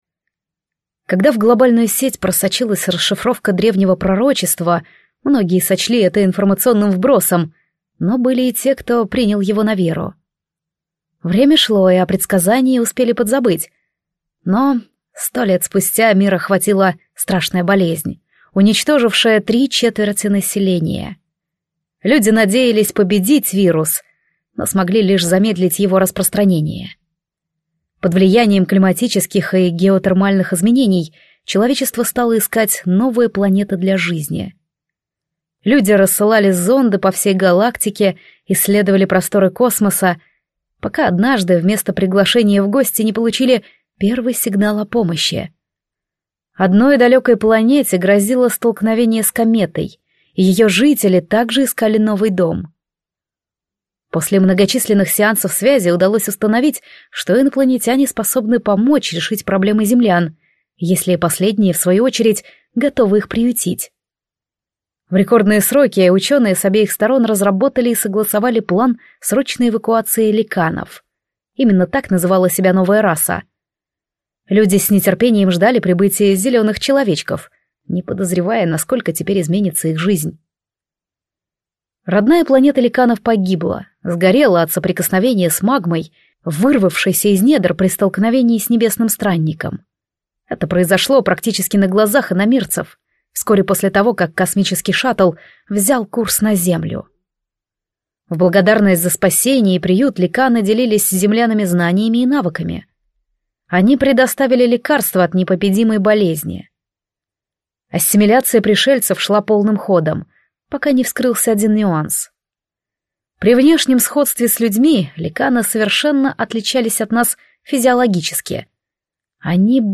Аудиокнига Темное наследие | Библиотека аудиокниг